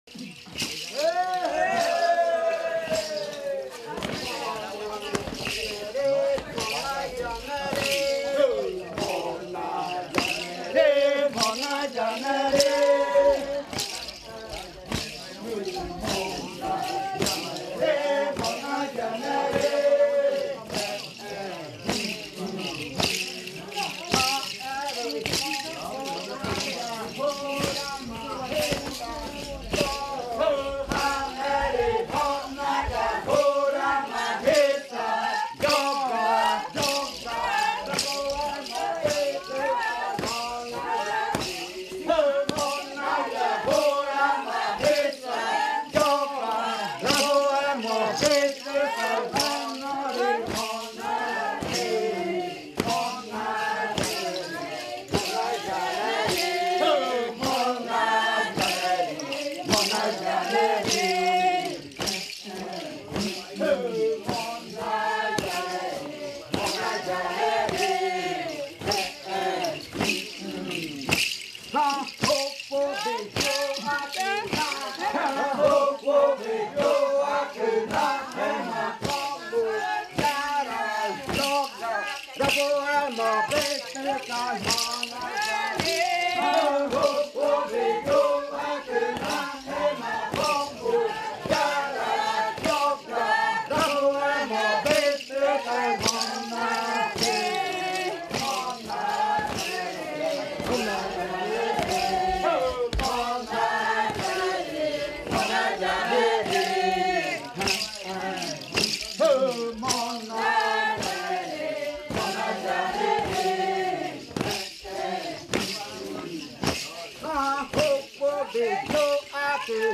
Canto de cierre de la variante muinakɨ
Leticia, Amazonas
con el grupo de cantores bailando en la Casa Hija Eetane. Este canto fue interpretado en el baile de clausura de la Cátedra de lenguas "La lengua es espíritu" de la UNAL Sede Amazonia.
with the group of singers dancing at Casa Hija Eetane.